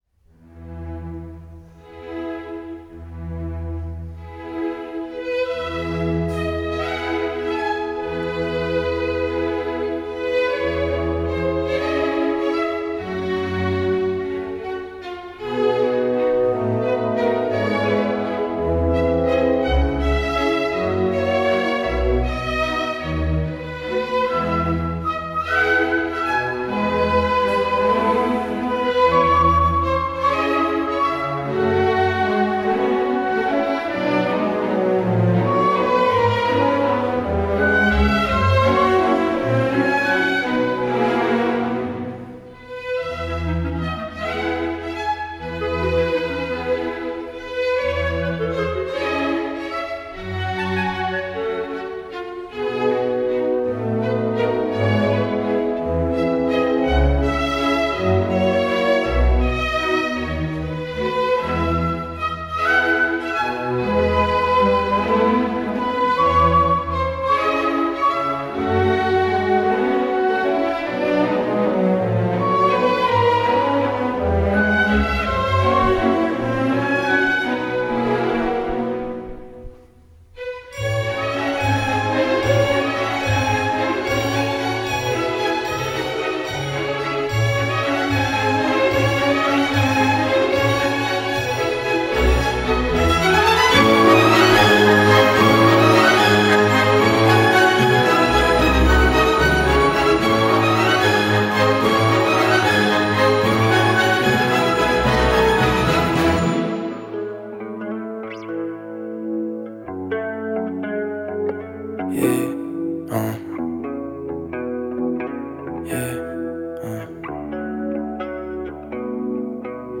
Lors de la soirée du 2 novembre 2024, chaque jeune a pu offrir son mix sur scène et enflammer la salle de la Halle à Dieulefit.